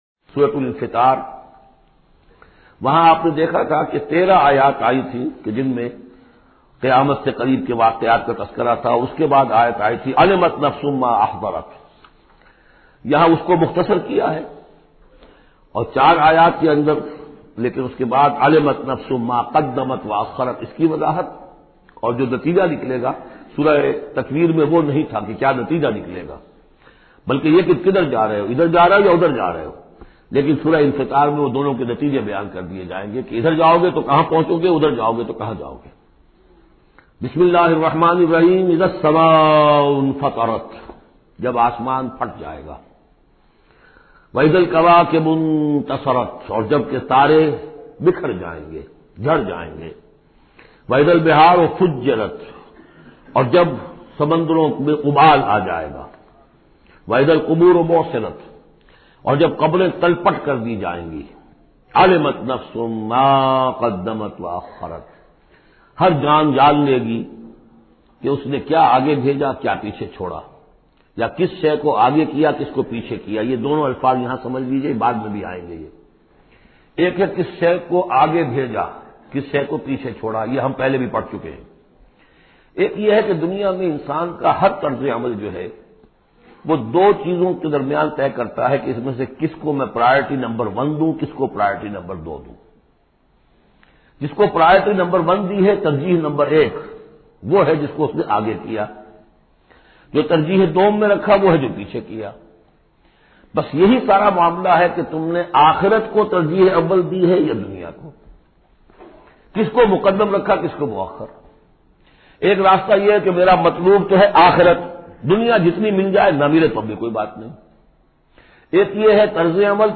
Surah Infitar, listen online mp3 urdu tafseer in the voice of Dr Israr Ahmed.